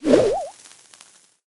dynamike_throw_02.ogg